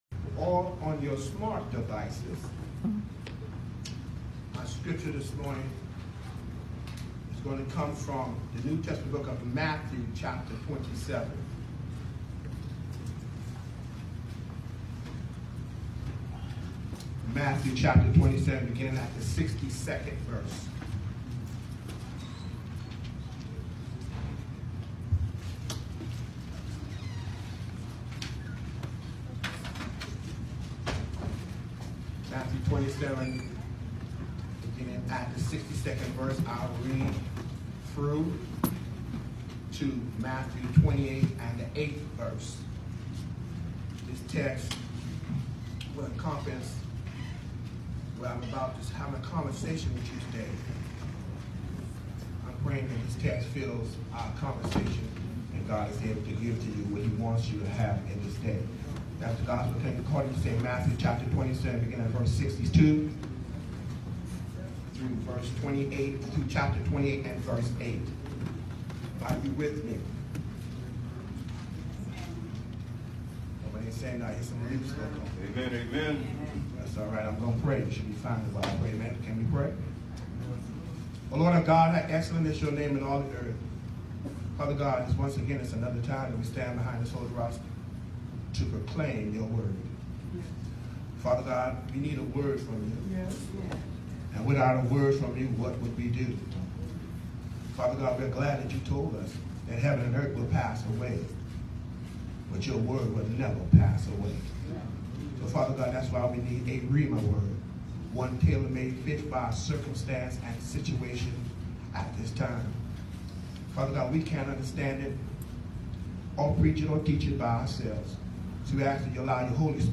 April 21, 2019 Sunday 11am (Resurrection Sunday) New Jerusalem MB Church Matthew 27:62 – 28:8 Message: It’s Not Over